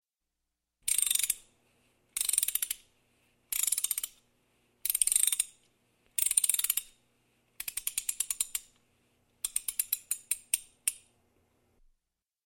Звуки куклы
Звук заведення ляльки